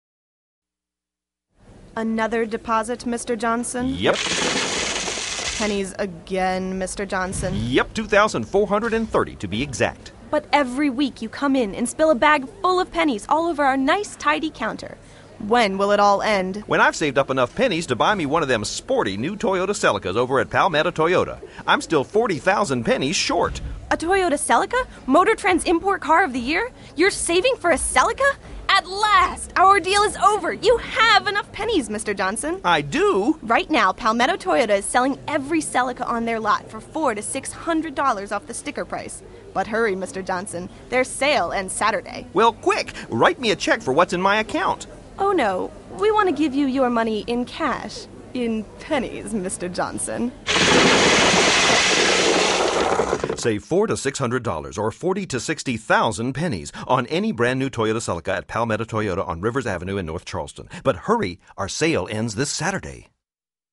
COPYWRITING/VOICEOVERS | Toyota, Arby's, City of Richmond I’ve written a wide range of commercials over the years, but for this sampler, I concentrated on some of the humorous spots done for a South Carolina Toyota dealer and for Arby’s in central Virginia.
Writer, Producer, Voiceover (2nd Voice)